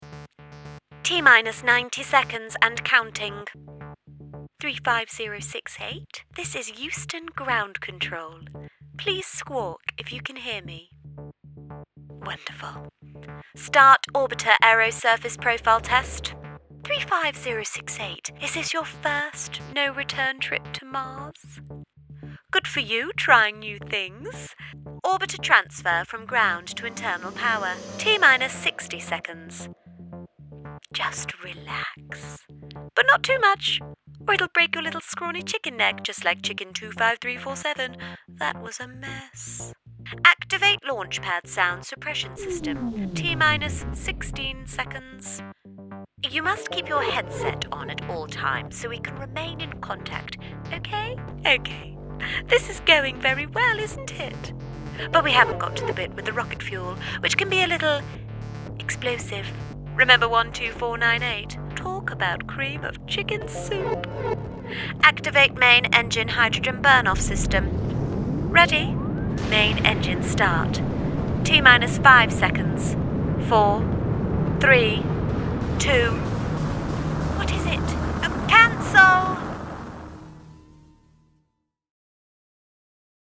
Northern, Relatable, Energetic.